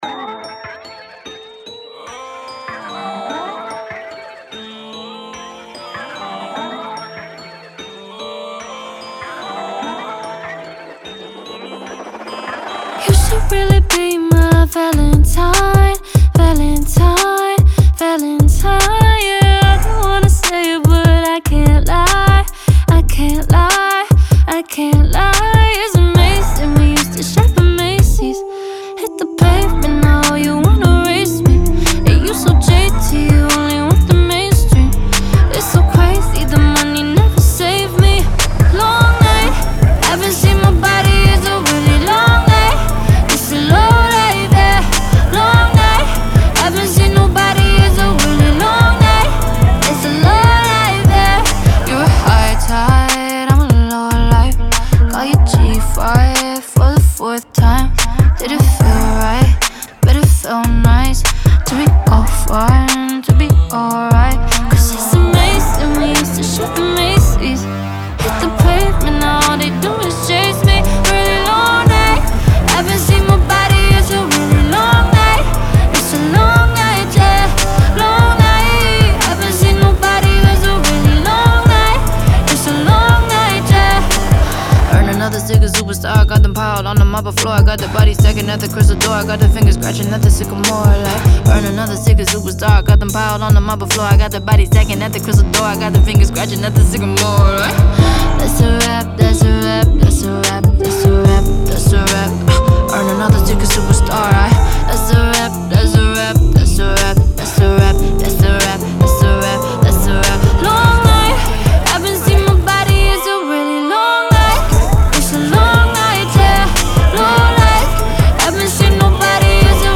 BPM147-147
Audio QualityPerfect (High Quality)
Trap song for StepMania, ITGmania, Project Outfox
Full Length Song (not arcade length cut)